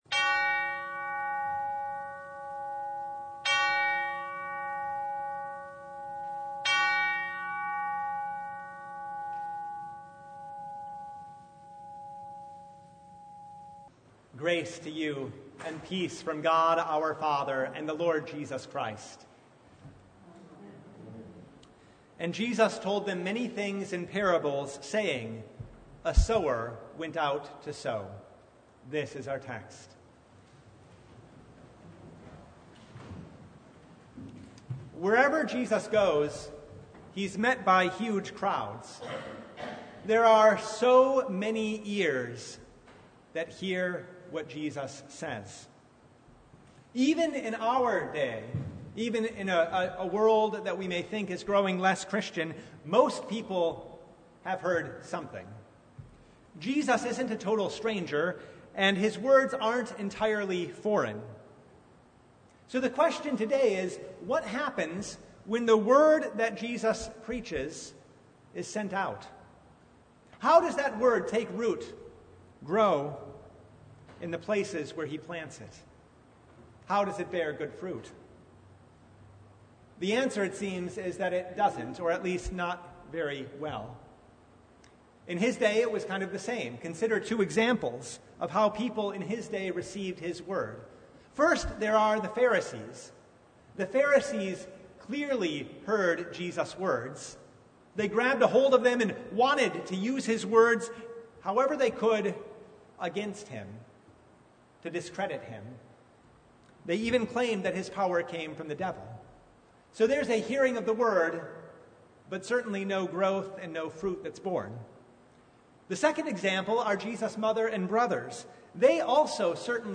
Service Type: Sunday